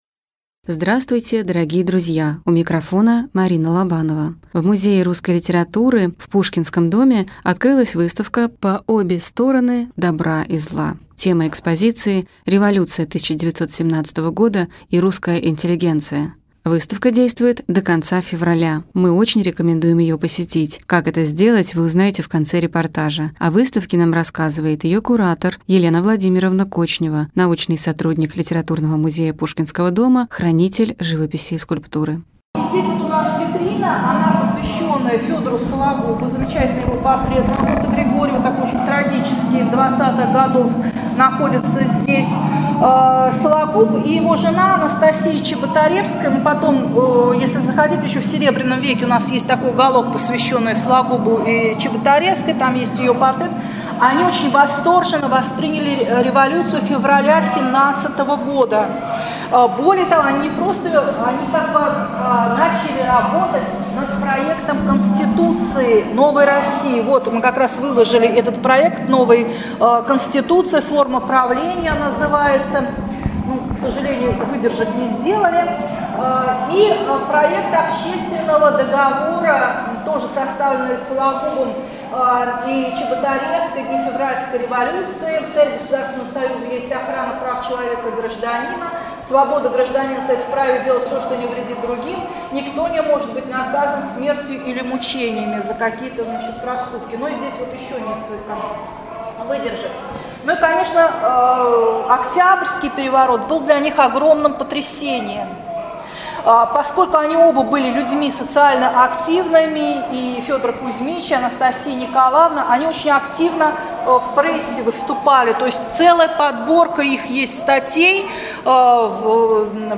Репортаж